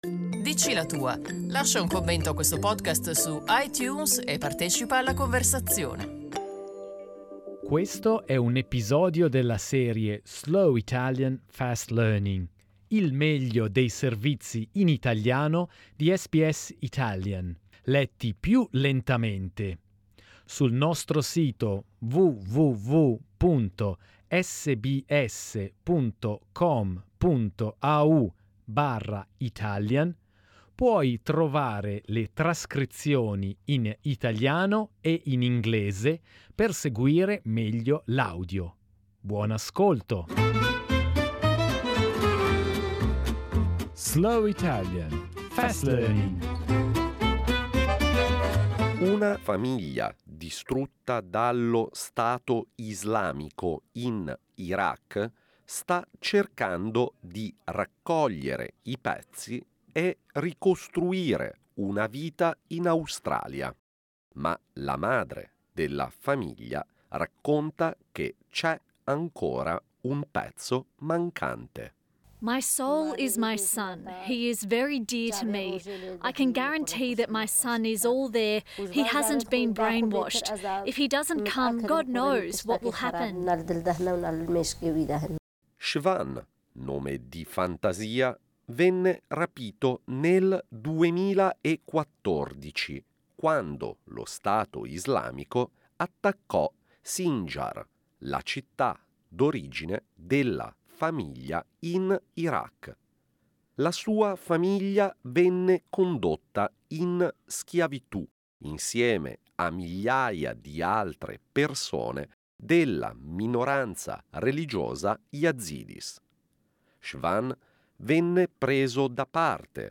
SBS Italian news, with a slower pace. This is Slow Italian, Fast Learning, the very best of the week’s news, read at a slower pace , with Italian and English text available .